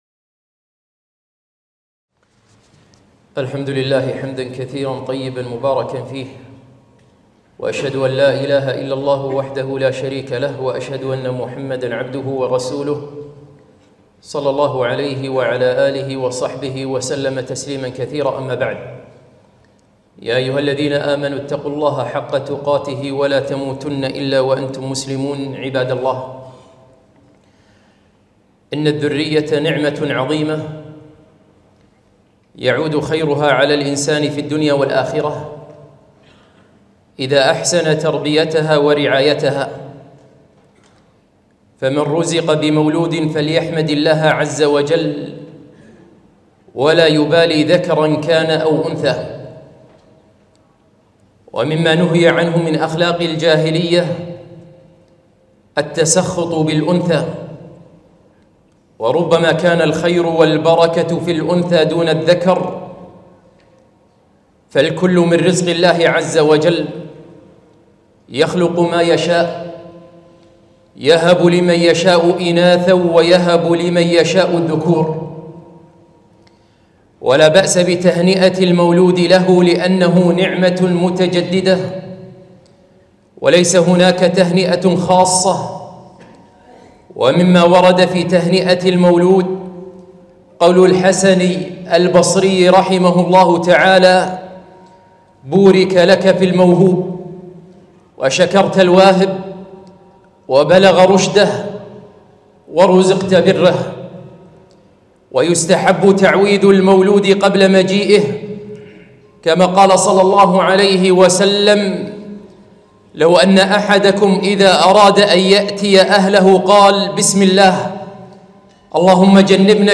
خطبة - من أحكام المولود